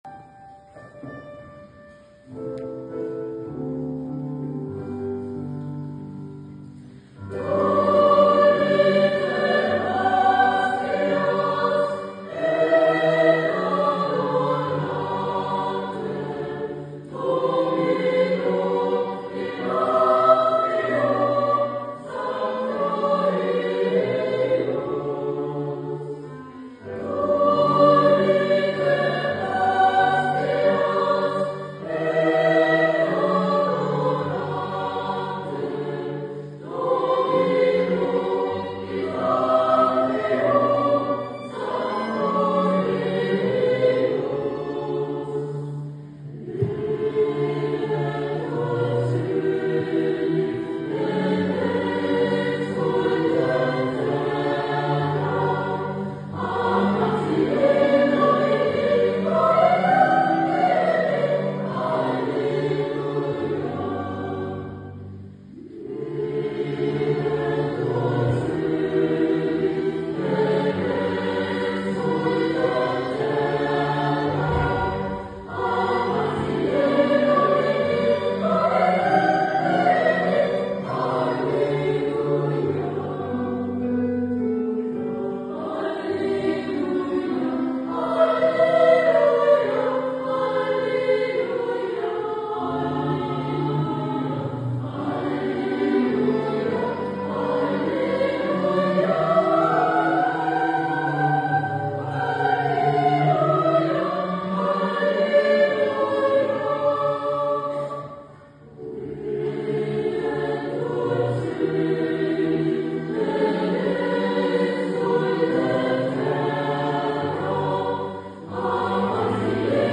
Chores „pro musica“
pro-musica_weihnachtsgruesse.mp3